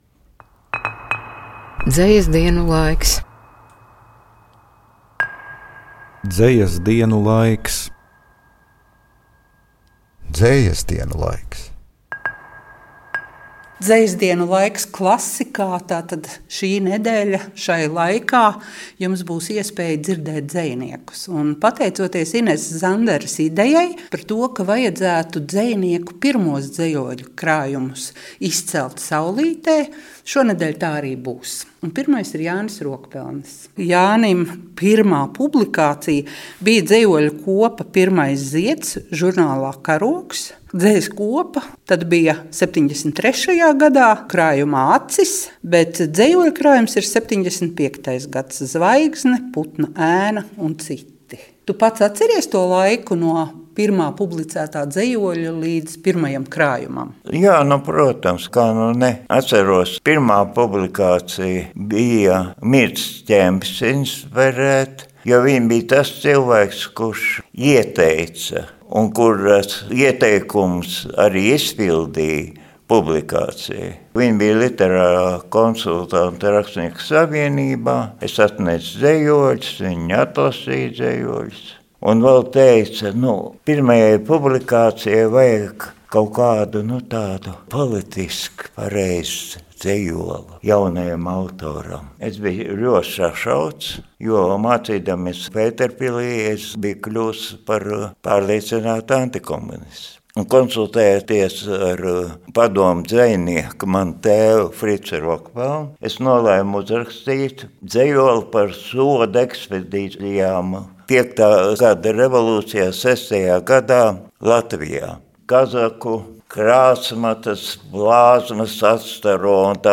"Klasikā" klāt tradicionālā septembra pārraide "Dzejas dienu laiks", kurā būs iespēja dzirdēt sarunas ar dzejniekiem.